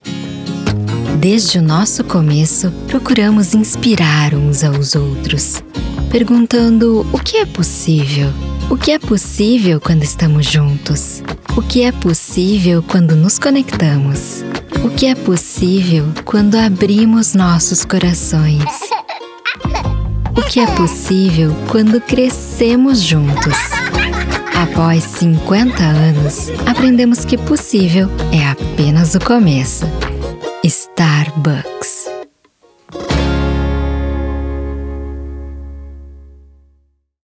Sprechprobe: Sonstiges (Muttersprache):
I'm professional Brazilian voiceover talent. My voice is natural, young, fun, confident, pleasant.